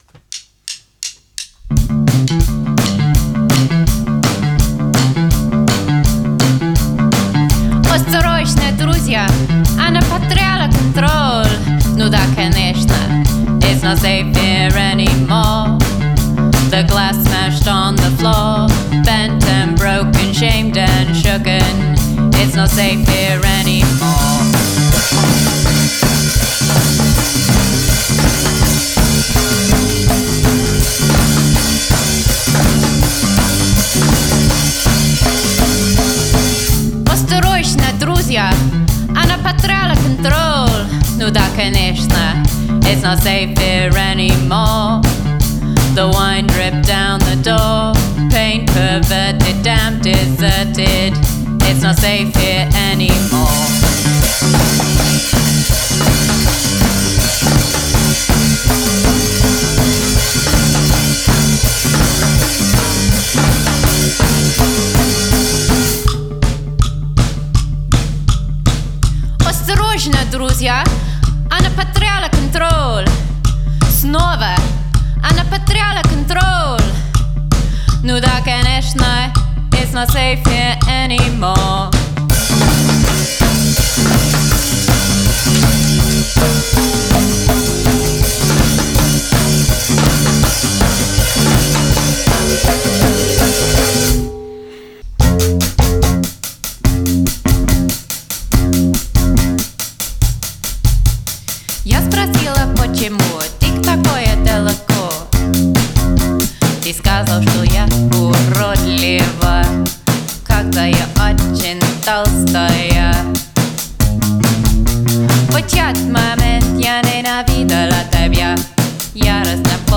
three piece post-punk/minimalist band
bass